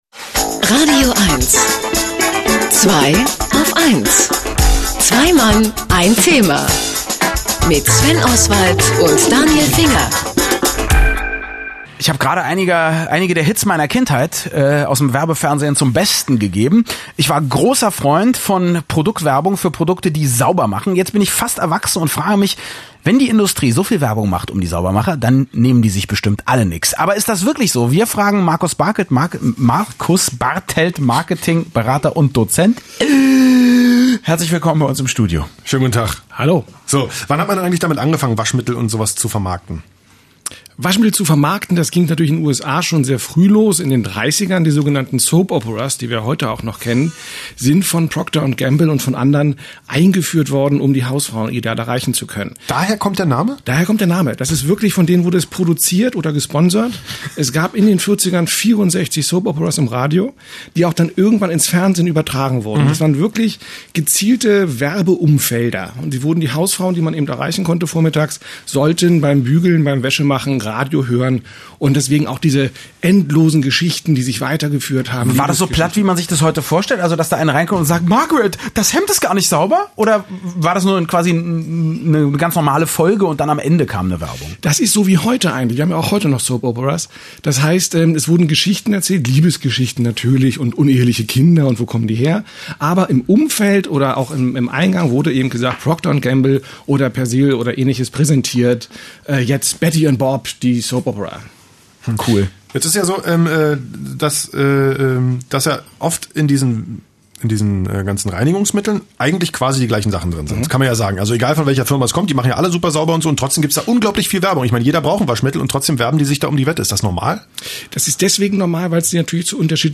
Fast 75 Interviews sind in diesem Zeitraum entstanden – und da ich mich selbst nicht mehr an alle erinnern konnte, werde ich nun in loser Reihenfolge, aber chronolgisch hier die Takes noch einmal posten.